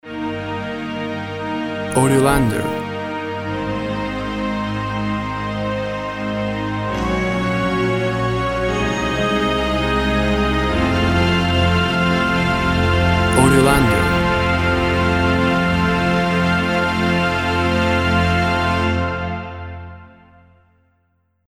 String orchestra rising to triumph.
Tempo (BPM) 68